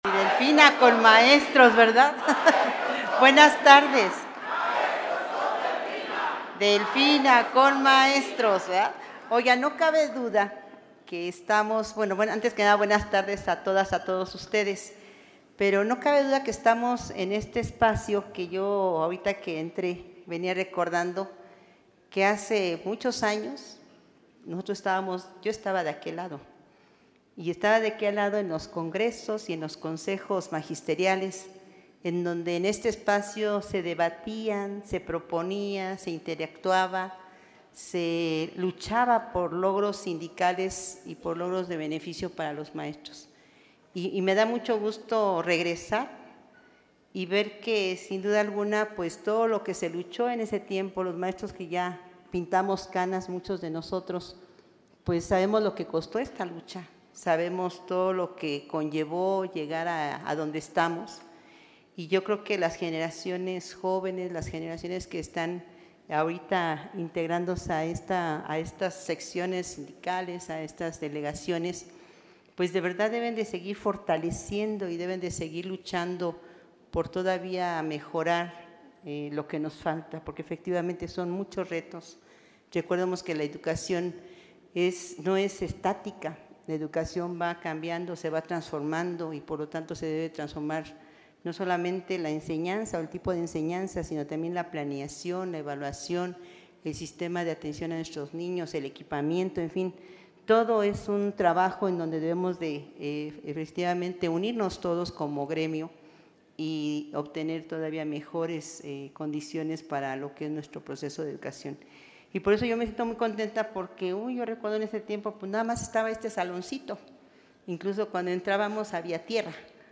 En el auditorio Profesor Agripín García Estrada del SMSEM, en la capital mexiquense, la mandataria destacó que en la transformación educativa es esencial valorar y dignificar el trabajo del personal de supervisión, proporcionándoles herramientas modernas que les permitan desempeñar su función de manera efectiva.
AUDIO-MENSAJE-COMPLETO_DGA_ENTREGA-DE-LAPTOPS-Y-PAQUETES-ESCOLARES-DE-EDUCACION-BASICA.mp3